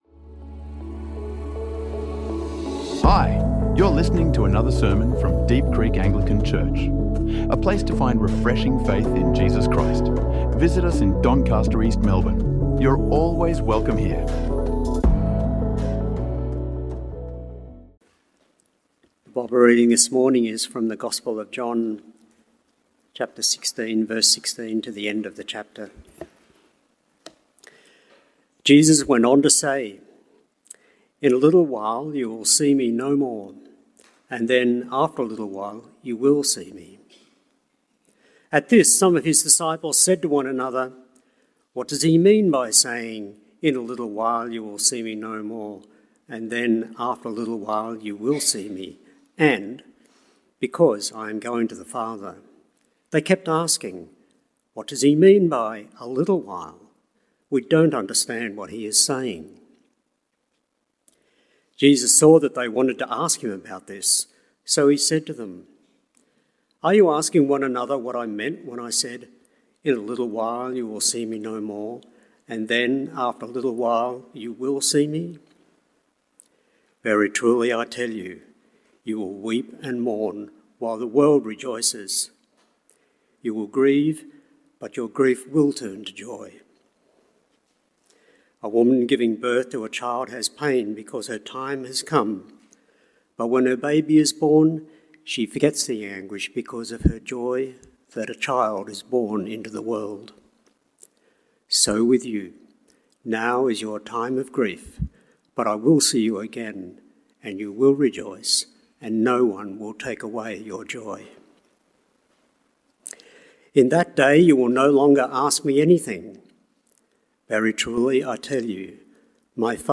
Jesus Secures Our Joy | Sermons | Deep Creek Anglican Church